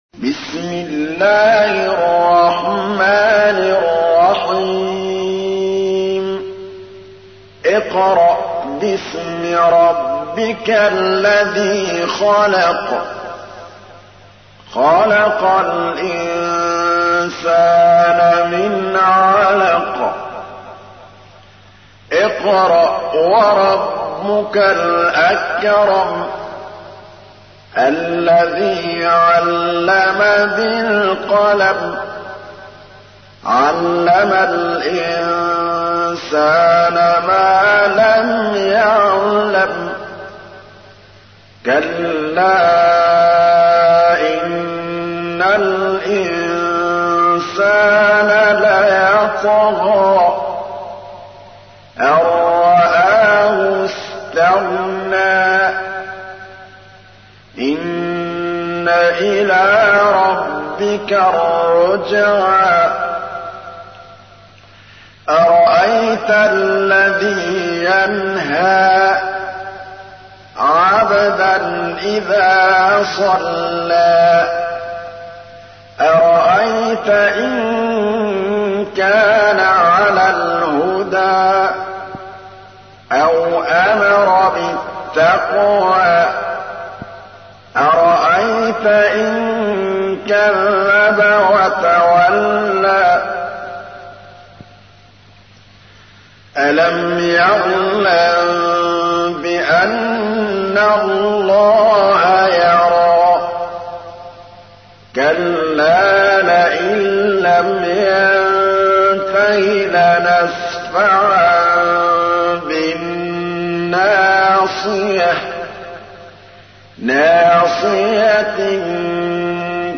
تحميل : 96. سورة العلق / القارئ محمود الطبلاوي / القرآن الكريم / موقع يا حسين